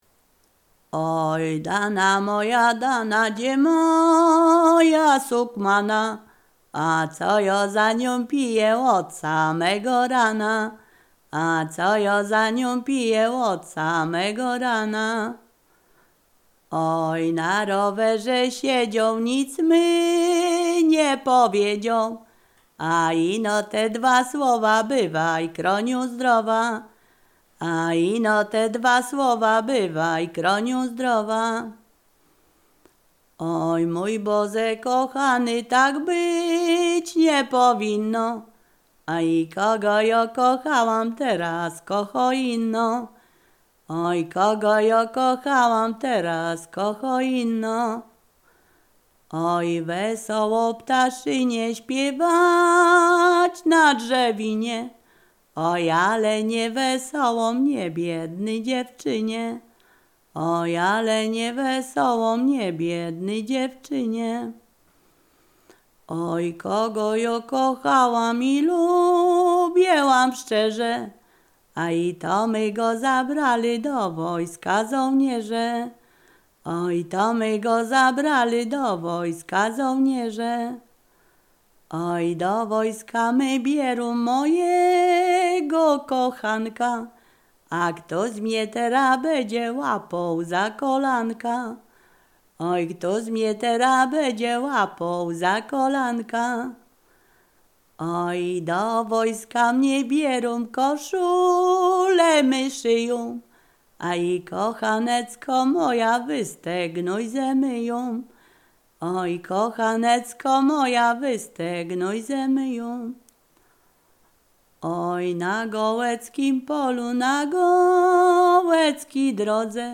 Ziemia Radomska
Przyśpiewki
miłosne weselne wesele przyśpiewki